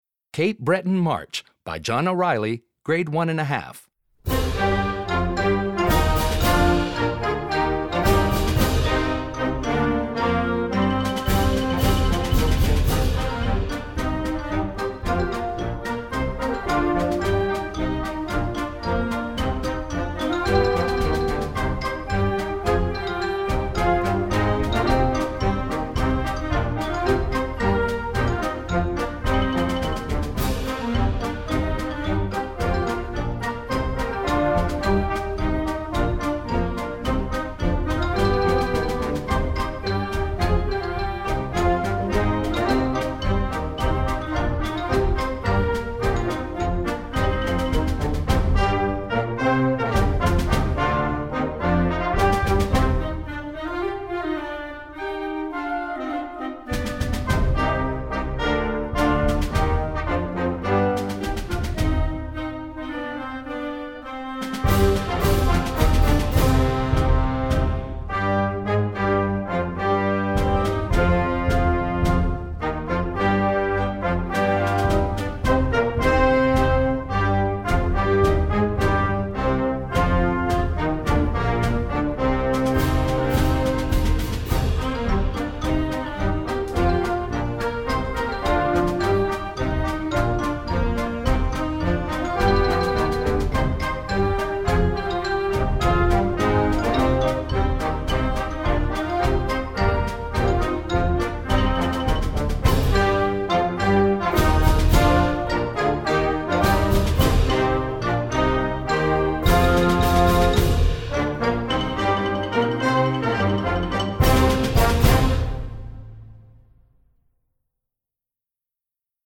Besetzung: Blasorchester
sprightly syncopated rhythms and natural minor tonality